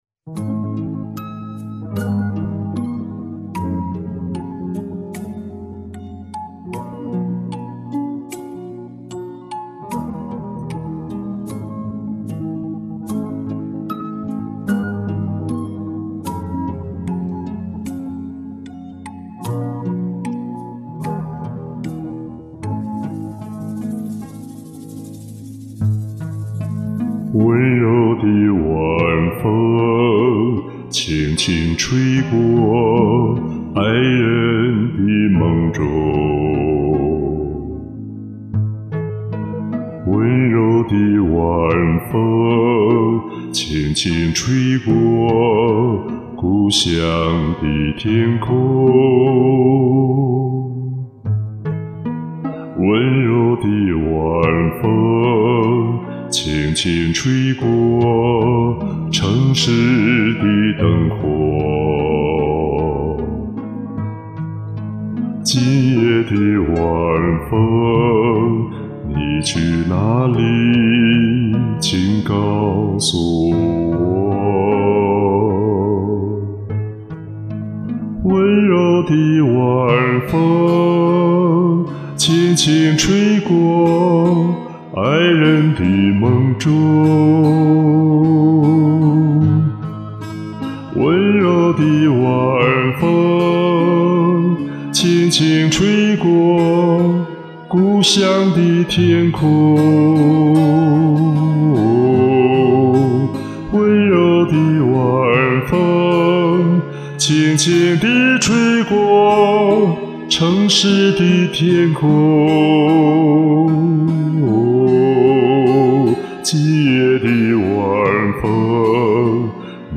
细腻感人，好听得很！
这个没听过的，好听的男中音版
轻轻柔柔，令人心醉！唱的温婉细腻